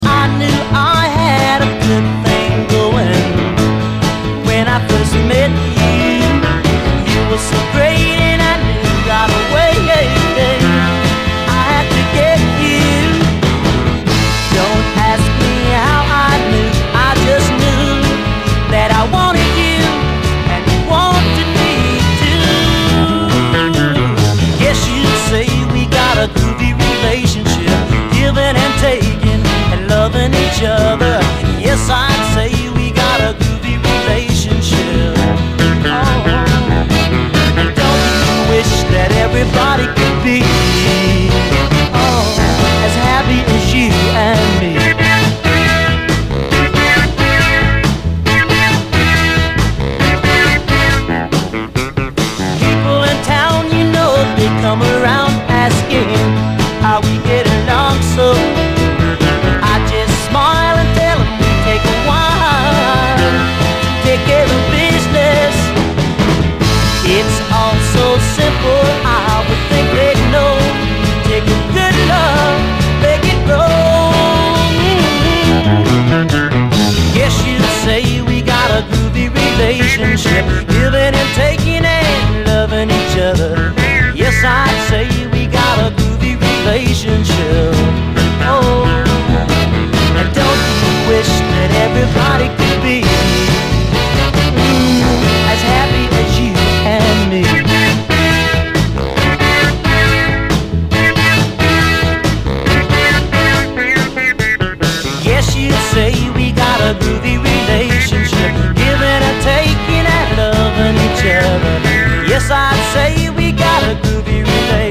SOUL, 60's SOUL, 60's ROCK, ROCK
白人ソフト・サイケ・アーティストが残した、甘酸っぱさ満点の輝ける青春ポップ・ノーザン〜ソフト・ロック！
無垢で甘酸っぱいメロディーが広がる、ソフト・ロックとしても素晴らしい青春60’Sポップ！